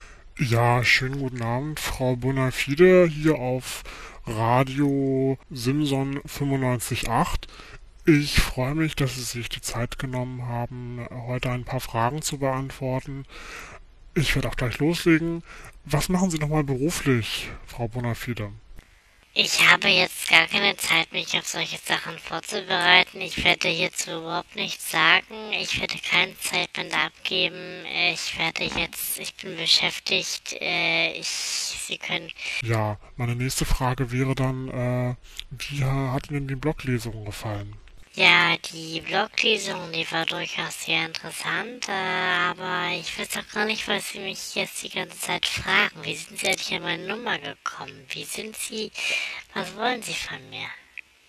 fiktives interview (mp3, 803 KB) bekam ich via E-Mail...